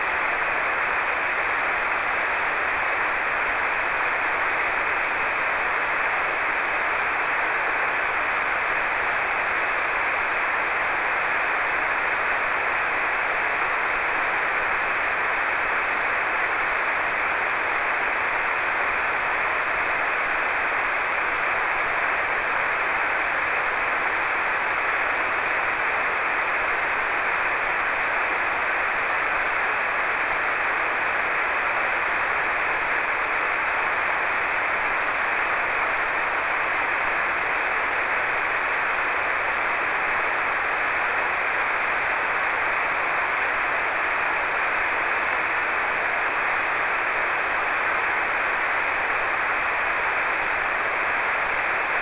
Prove di ascolto WSJT - 8,9 aprile 2011
Antenna: 16JXX, Apparato: FT897
nessun preamplificatore
N.B.: Solo nel primo file si può ascoltare un flebile "suono"... per tutti gli altri solo utilizzando WSJT si ha evidenza dei segnali ricevuti.